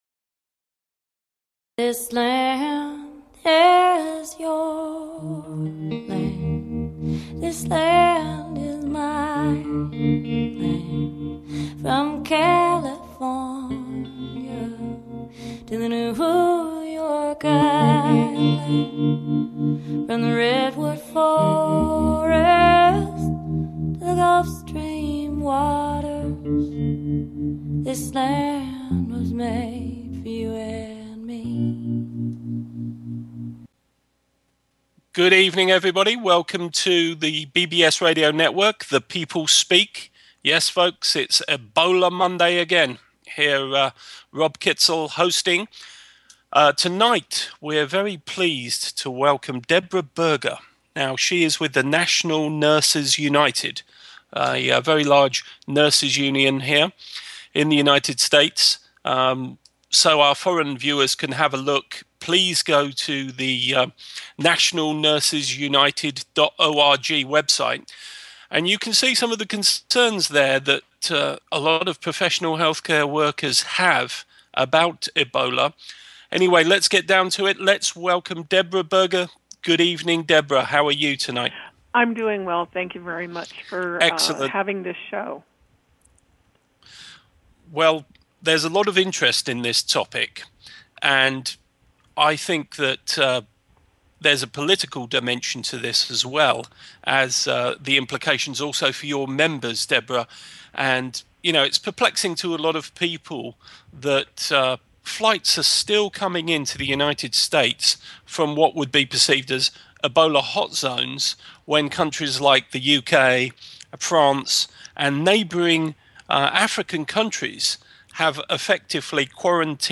Talk Show Episode, Audio Podcast, NATIONAL NURSES UNITED Addresses EBOLA